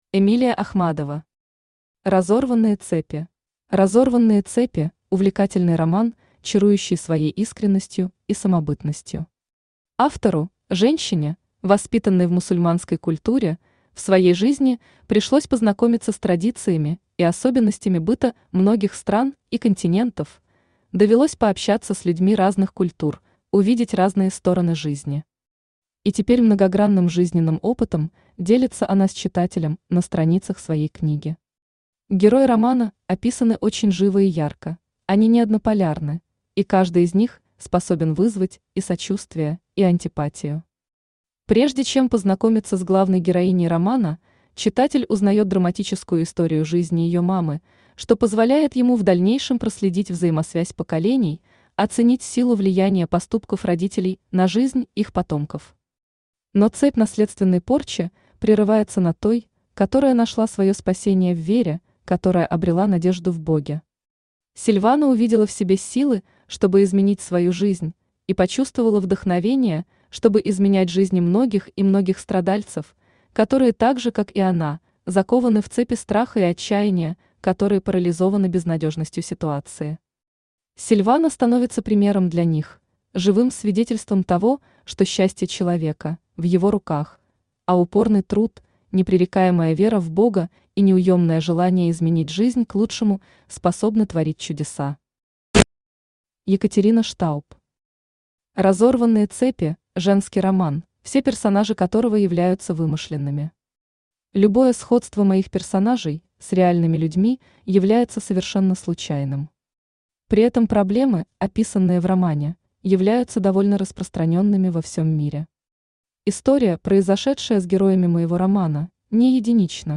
Аудиокнига Разорванные цепи | Библиотека аудиокниг
Aудиокнига Разорванные цепи Автор Эмилия Ахмадова Читает аудиокнигу Авточтец ЛитРес.